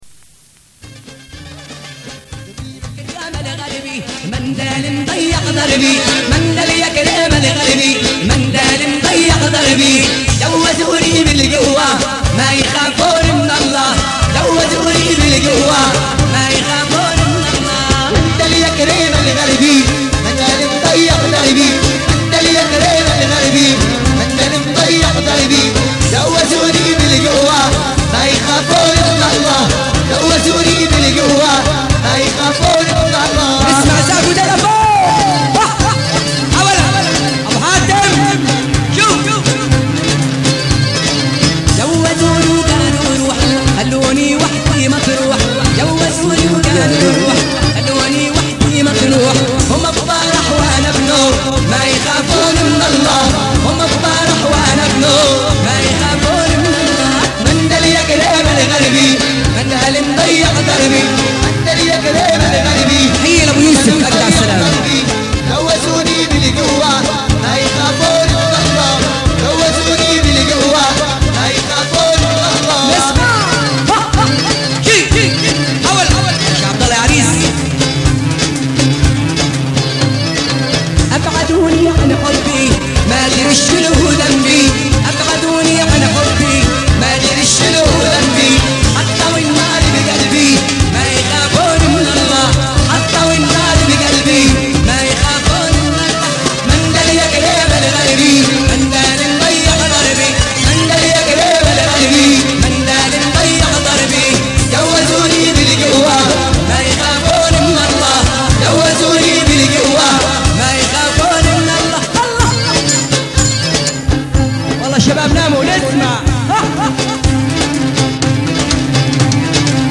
أغاني فلسطينيه